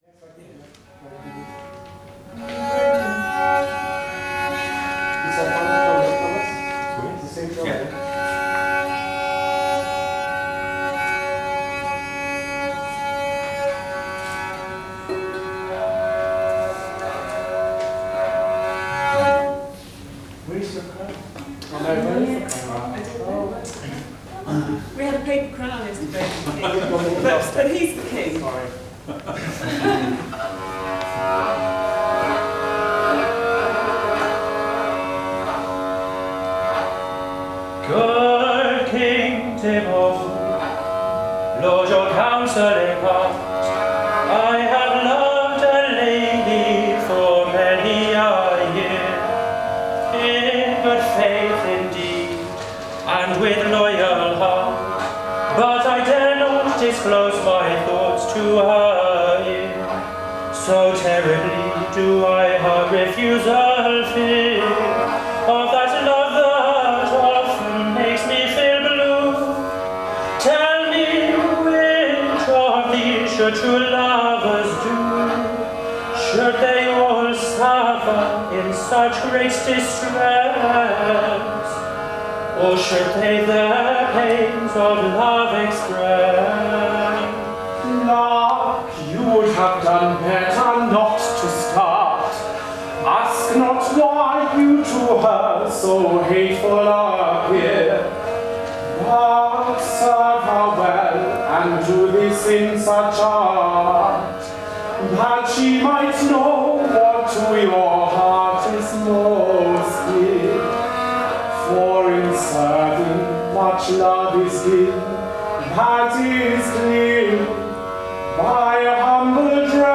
A third post from the performance workshop with graindelavoix, sponsored by the Leverhulme Trust and held at St Hugh’s College, Oxford in March 2017.
jp30-english.wav